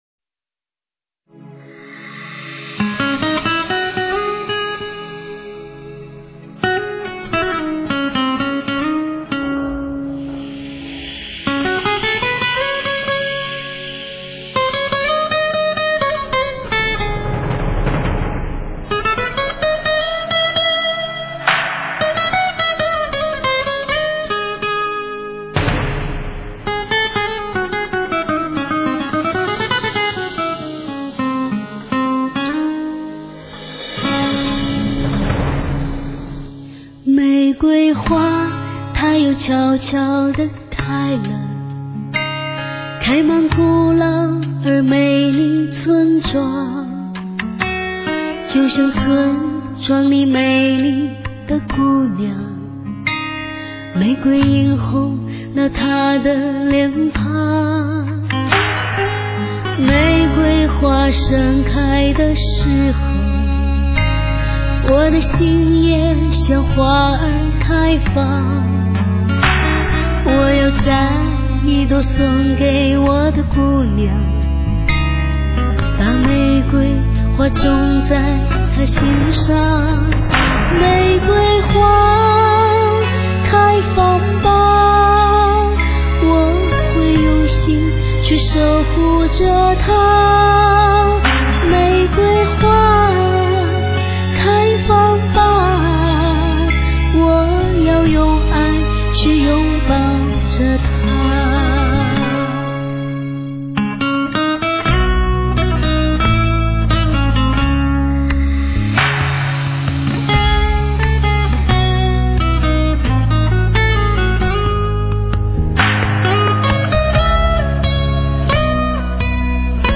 天籁的女声，醉人的音乐，是情人送情人的最珍贵的信物......